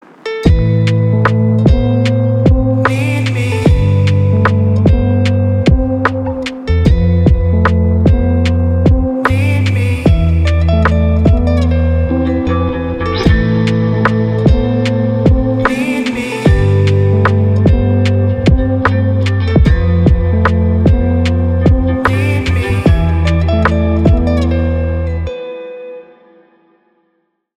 Рэп рингтоны
спокойные мелодичные гитара
расслабляющие